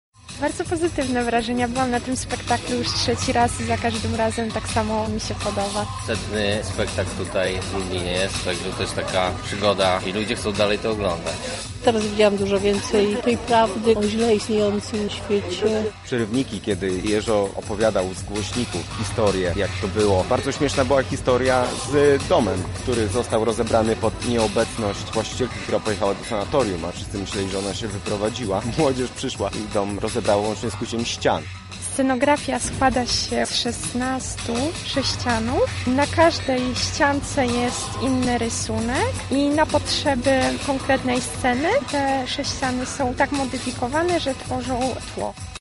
Na miejscu była nasza reporterka.